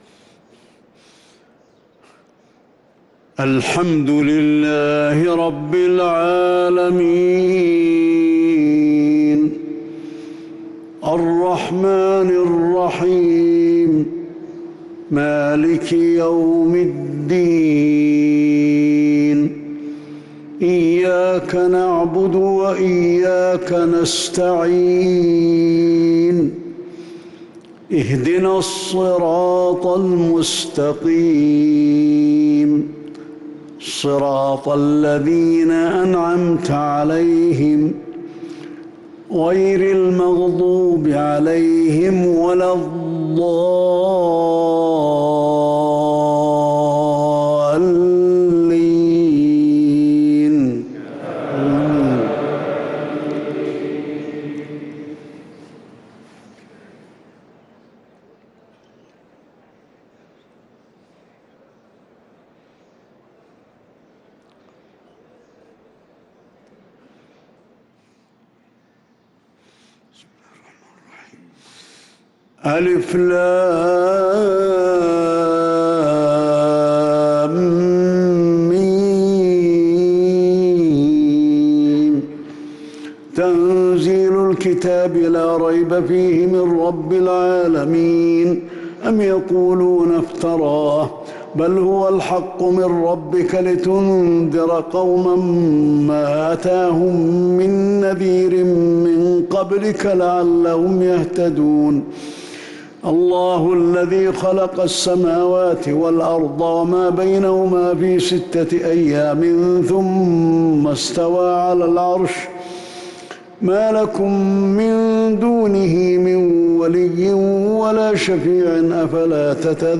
صلاة الفجر للقارئ علي الحذيفي 9 ذو الحجة 1443 هـ
تِلَاوَات الْحَرَمَيْن .